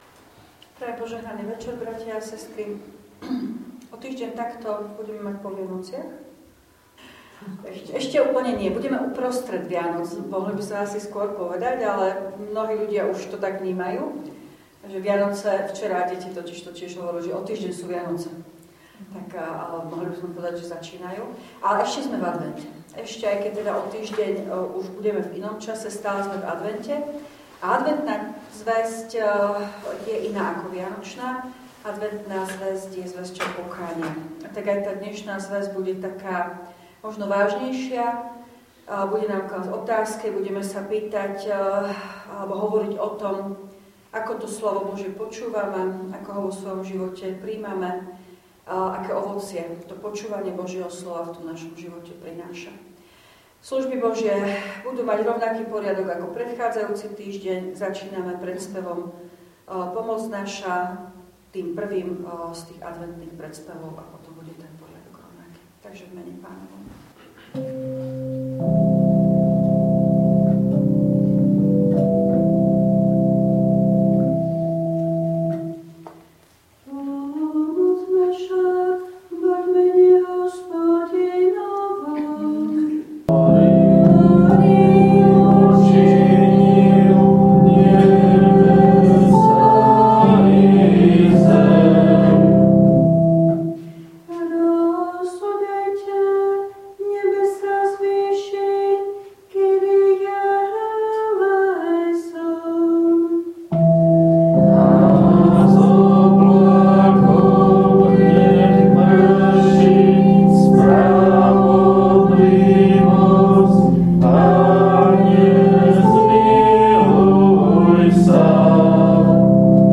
Večerné adventné služby Božie_18.12.2024
V nasledovnom článku si môžete vypočuť zvukový záznam z večerných adventných služieb Božích.